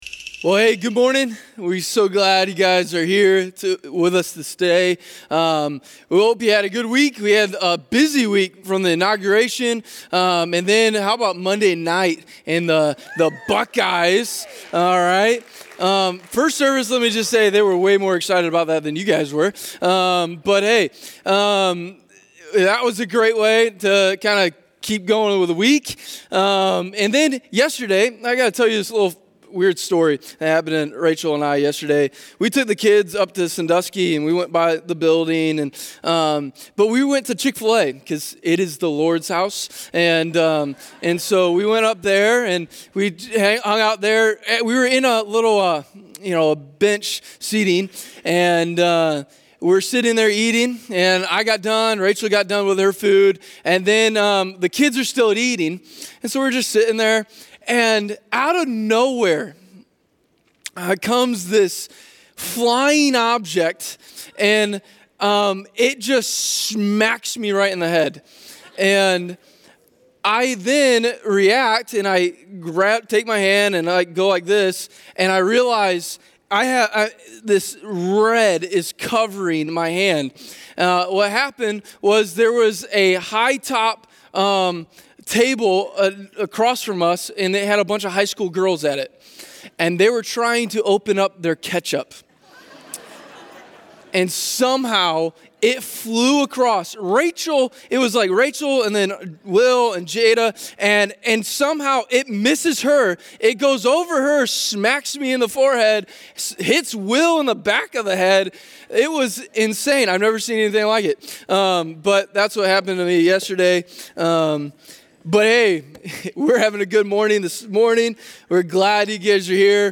teaching from Genesis 1. The passage reminds us we are made in God’s image and created to be in relationship with God. Jesus wants a relation with us and to show the world Gods love.